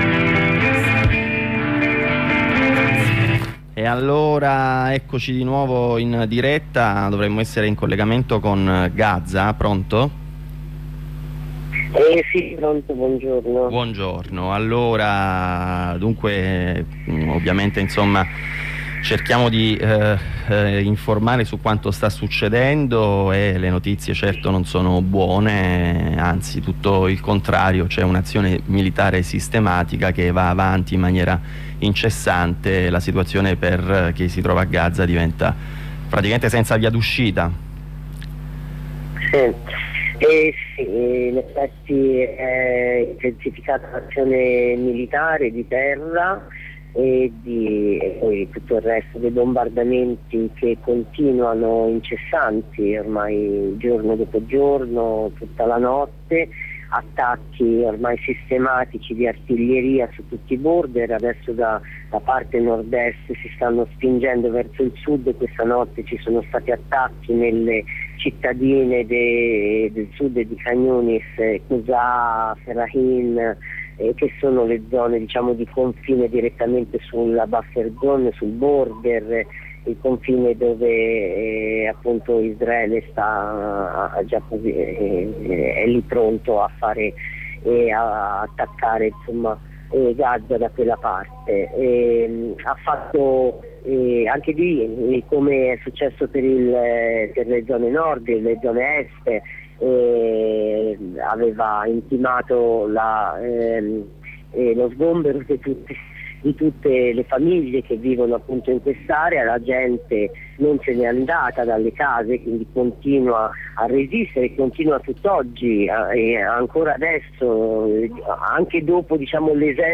Corrispondenza da Parigi